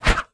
swing1.wav